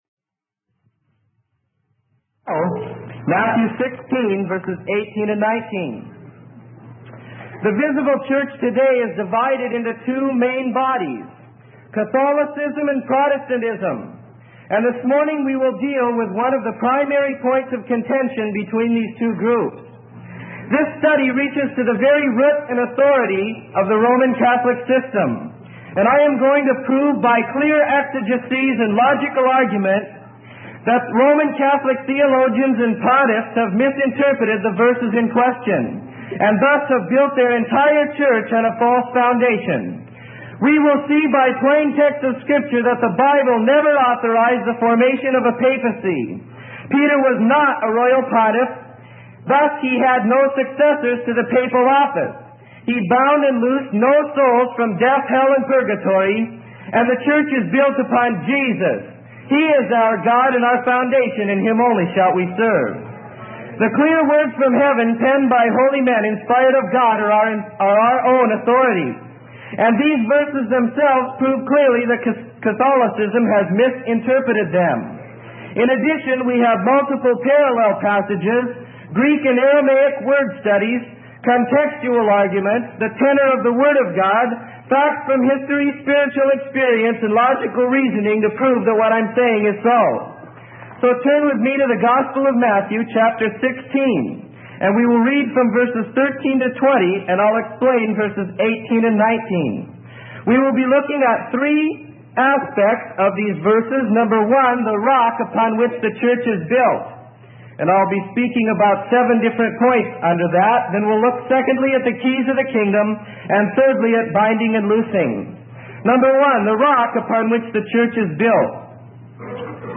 Sermon: Was Peter the First Pope?